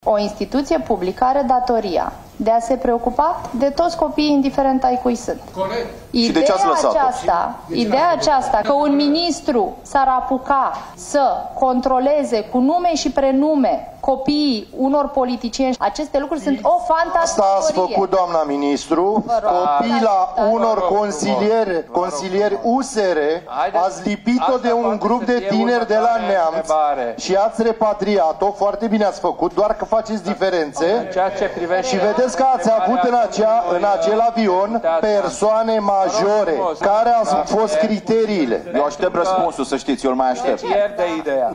Scandal în Comisia de Politică Externă a Camerei Deputaților, în care este audiată ministra de Externe.